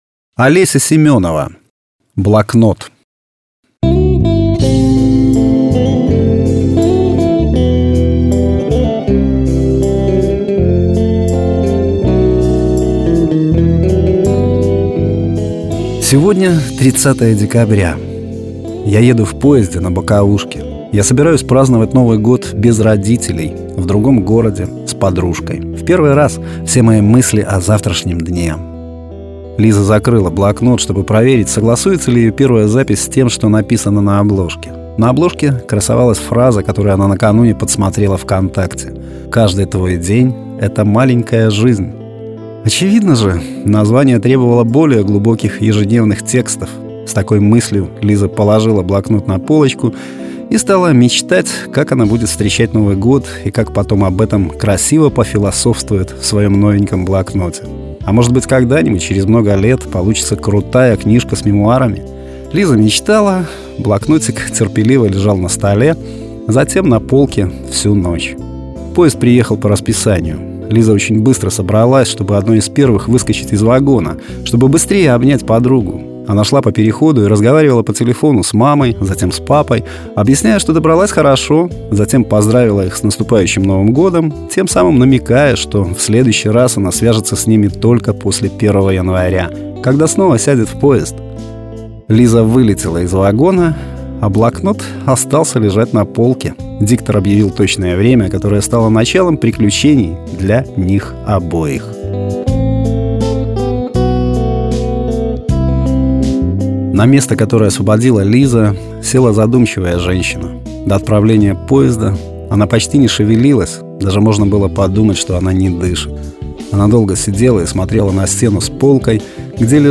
Аудиокниги
Аудио-Рассказы
Качество: mp3, 256 kbps, 44100 kHz, Stereo